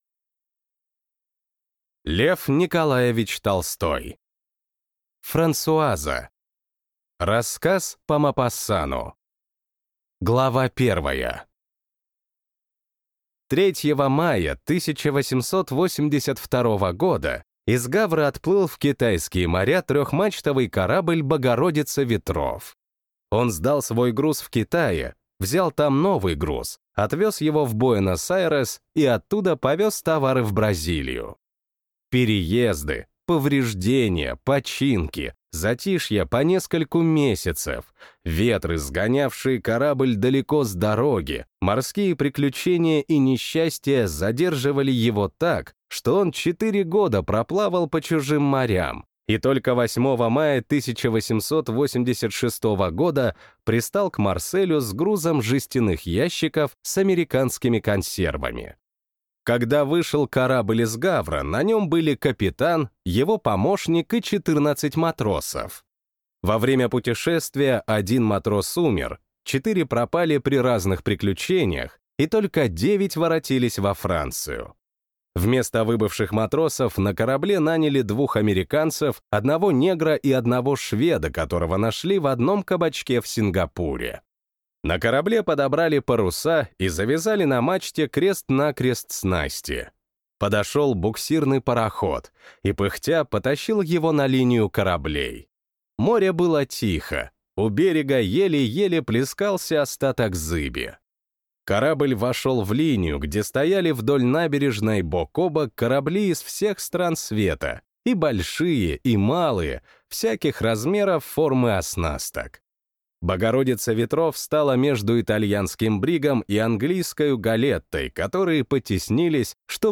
Аудиокнига Франсуаза